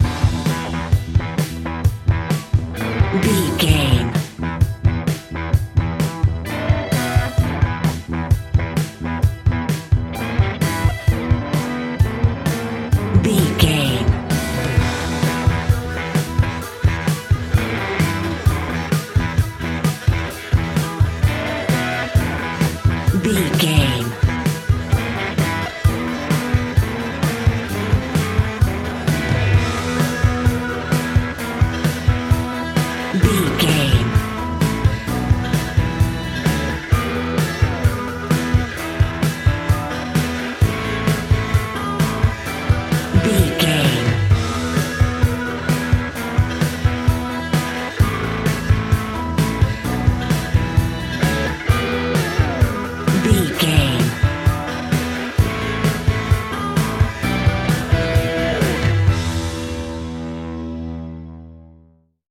Ionian/Major
E♭
hard rock
blues rock
distortion